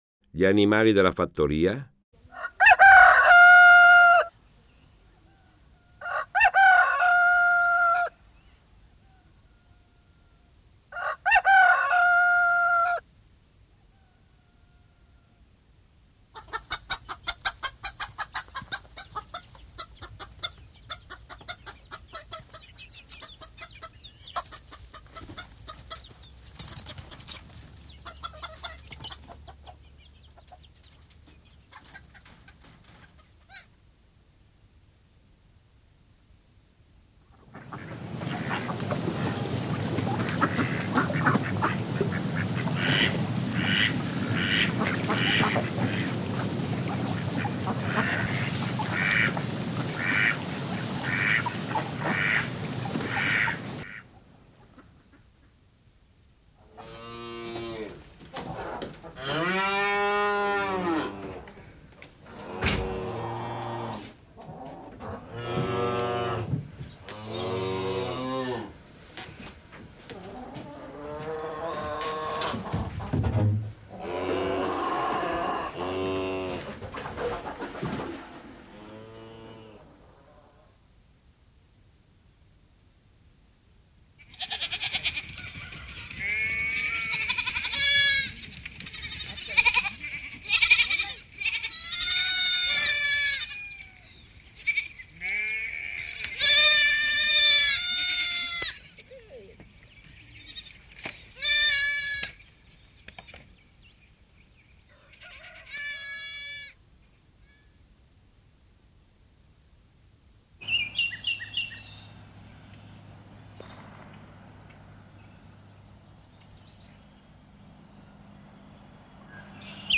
Suoni della fattoria
Suoni-animali-della-fattoria.mp3